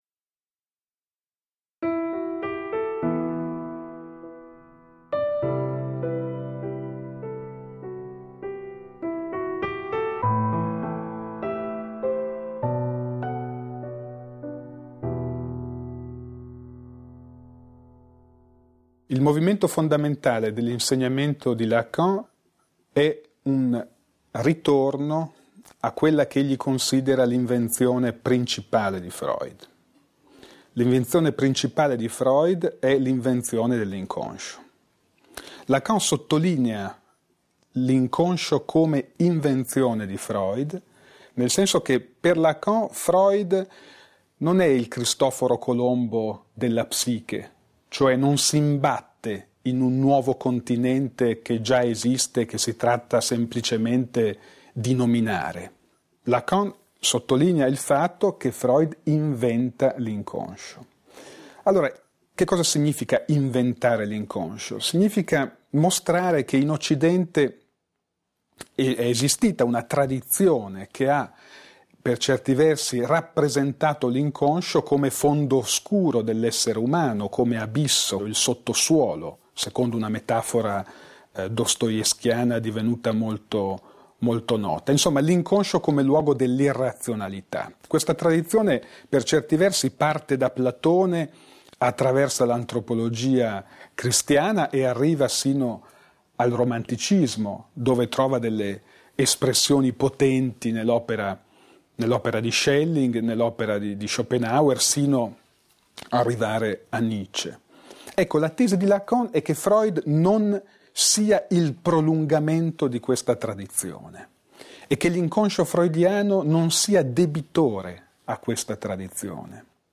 Massimo Recalcati su: inconscio, Jacques Lacan, linguaggio, parola, desiderio, padre, legge.Mp3 (da una lezione tenuta all'interno dei Dvd LA PSICOLOGIA, La Repubblica - L'Espresso 2012